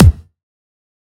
normal-hitwhistle.ogg